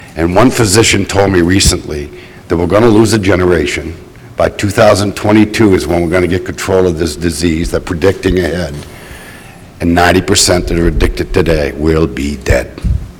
MAINE GOVERNOR PAUL LEPAGE SAYS A PHYSICIAN TOLD HIM THAT BY 2022 WE ARE GOING TO GET A HOLD OF THIS DISEASE BUT BY THAT TIME A GENERATION WILL HAVE BEEN LOST TO IT.